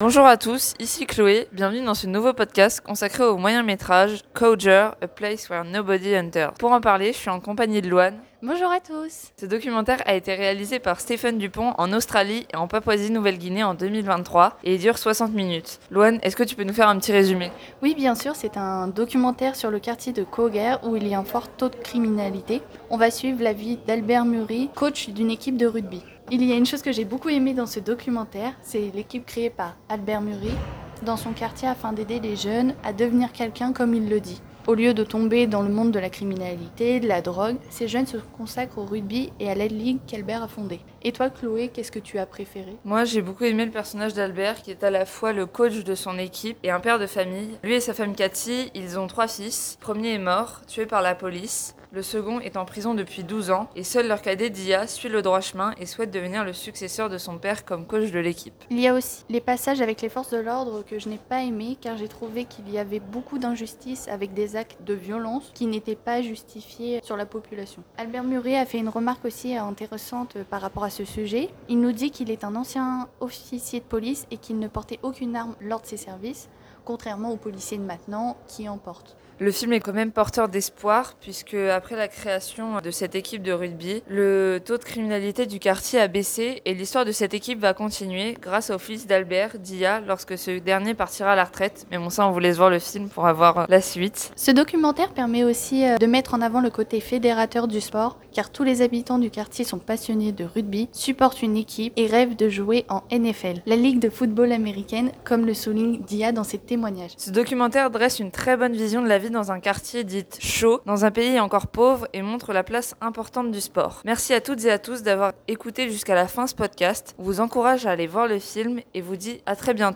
une jeune critique
une webjournaliste